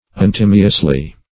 untimeously - definition of untimeously - synonyms, pronunciation, spelling from Free Dictionary Search Result for " untimeously" : The Collaborative International Dictionary of English v.0.48: Untimeously \Un*time"ous*ly\, adv. Untimely; unseasonably.